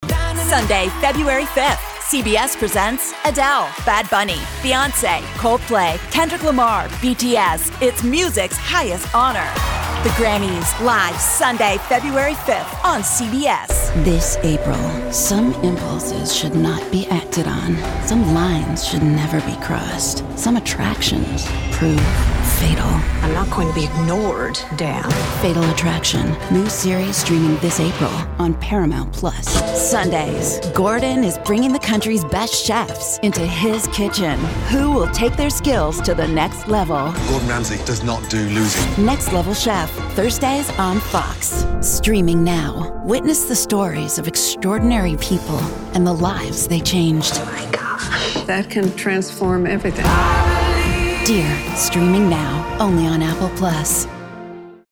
Tengo mi propio estudio con equipos de última generación;
DAW - Protools, Mic - Sennheiser MKH 416, Preamp - Universal Audio 6176, Interfaz - Volt 176.
Mediana edad